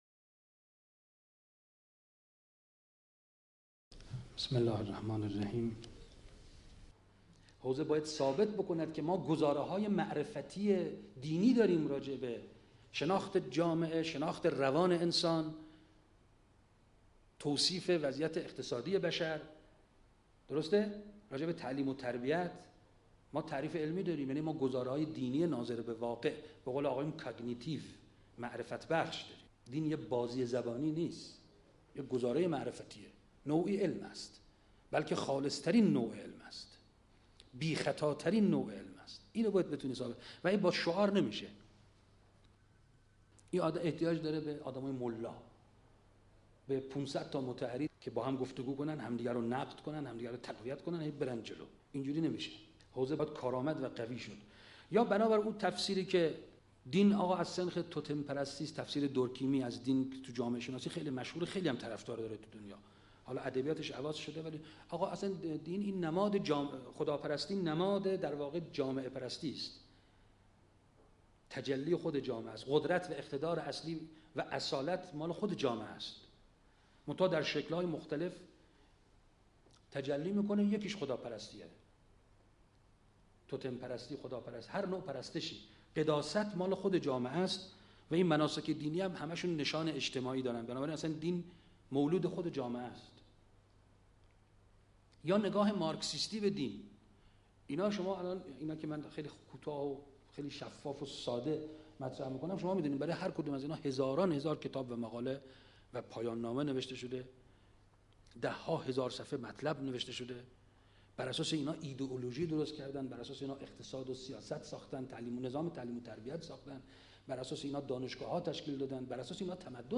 نشست وحدت حوزه و دانشگاه و چالش های معاصر روشنفکری اسلامی _ مشهد آذر 86